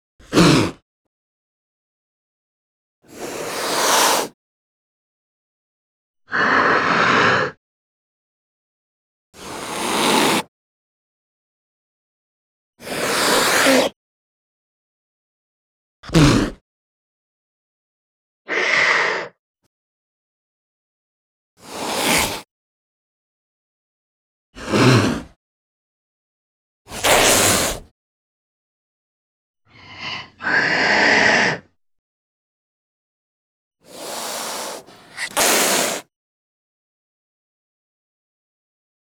animal
Ocelot Spits and Hisses